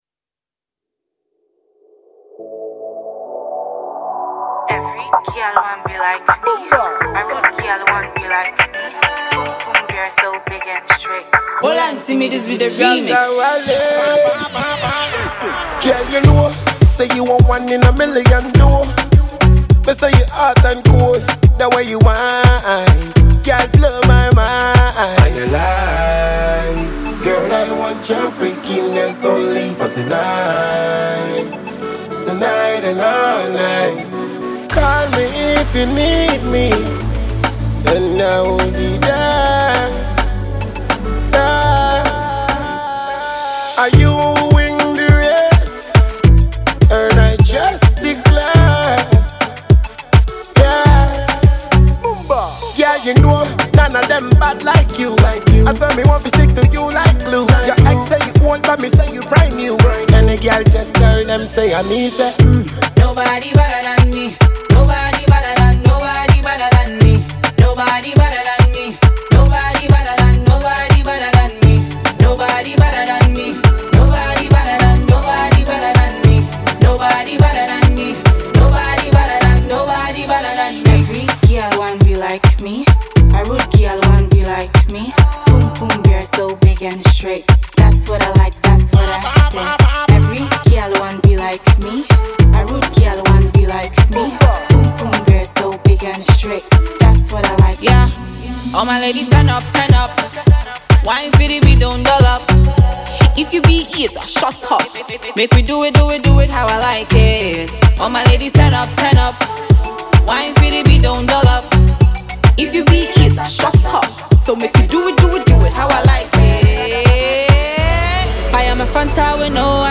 Ghanaian dancehall songstress